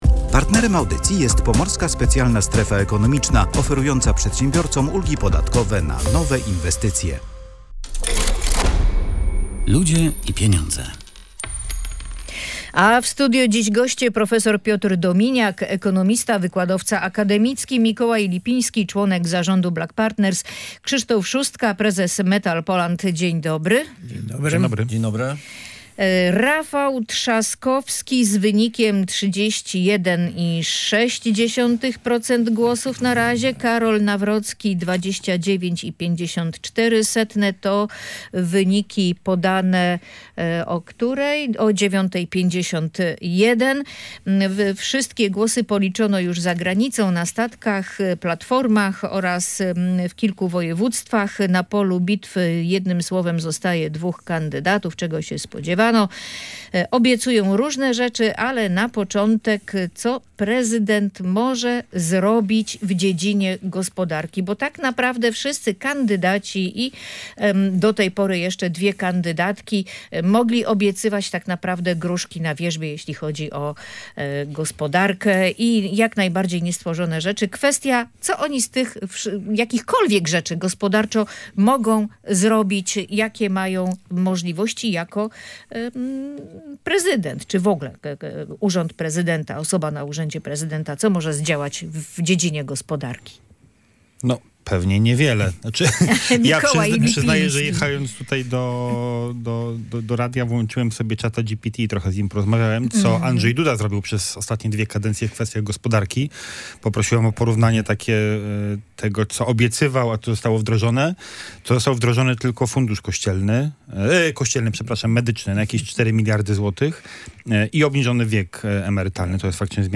Kandydaci na prezydenta mogą składać różne obietnice, ale projekty ich ustaw musi przyjąć parlament - tak kampanię wyborczą komentują goście